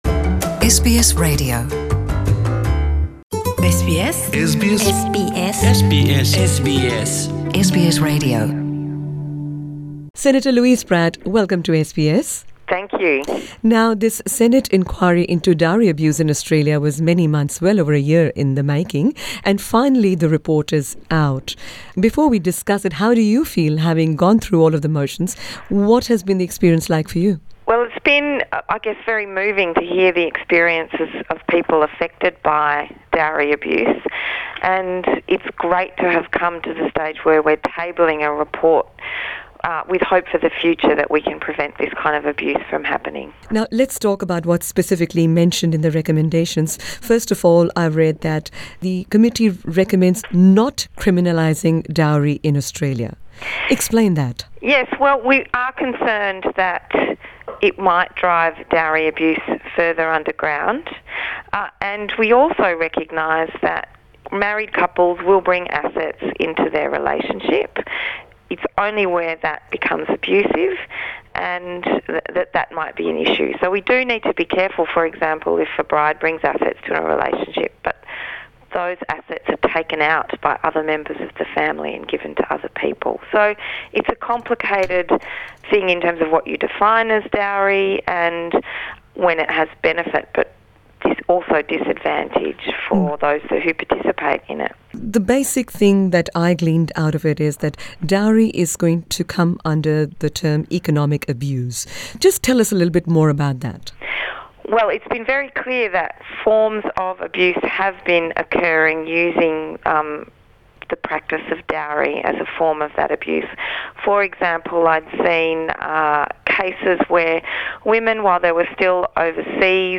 In a interview with SBS Punjabi, Senator Loiuse Pratt, the Chair of the Senate inquiry into the prevalence of dowry abuse in Australia, talks us through the recommendations made by the committee, and has commended SBS Punjabi's role in sparking a national discussion on the extent of dowry abuse in Australia.